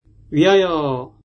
韓国人講師の音声を繰り返して聞きながら発音を覚えましょう。
発音と読み方
위하여 [ウィハヨ]